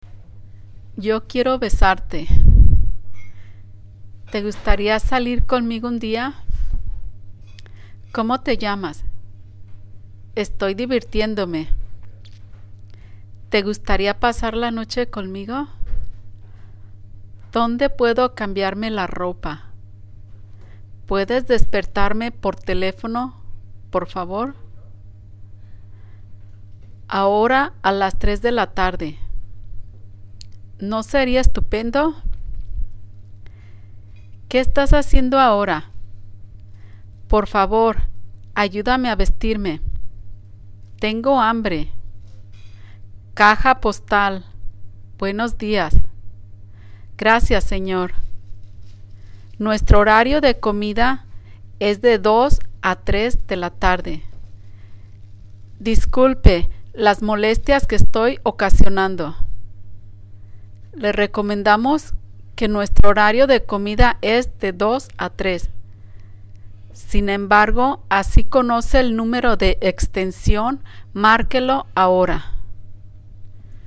You can have fun with your friends and lovers by listening to these romantic phrases that were recorded by a Spanish speaking native.